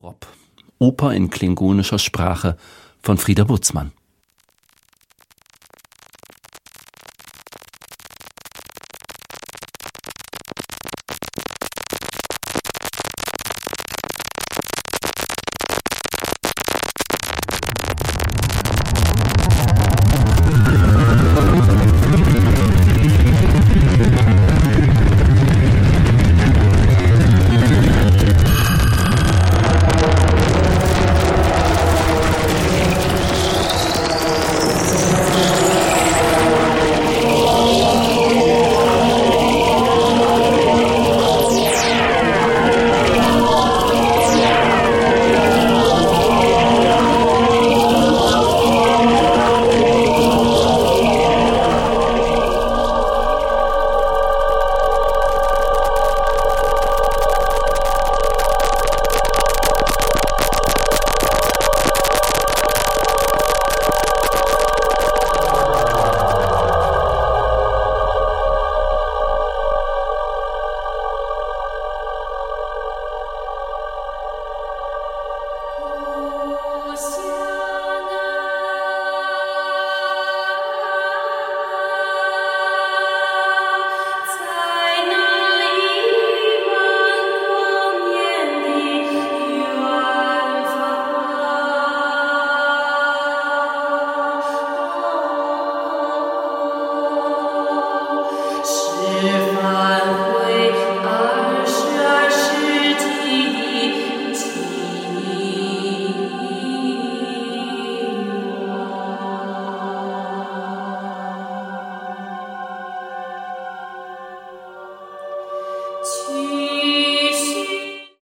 It is orchestral music produced with electronic devices
The vocals are Klingon and Chinese."